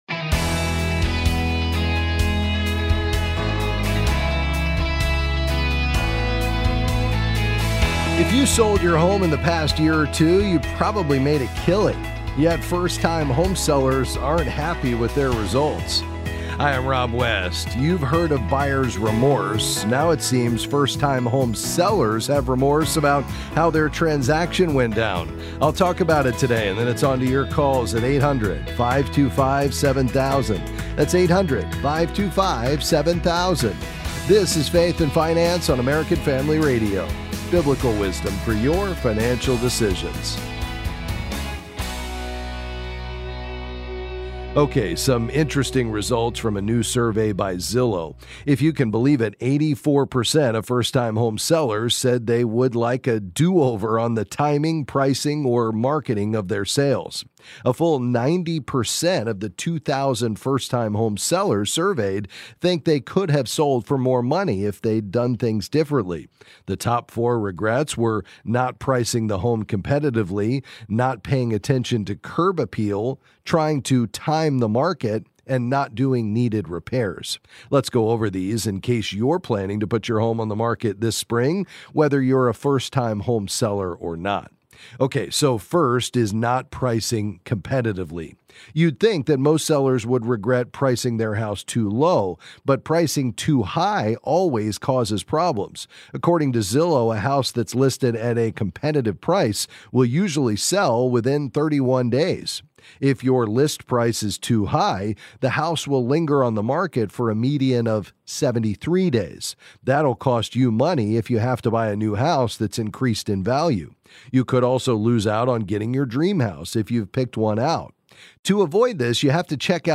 Then he’ll answer your calls on various financial topics.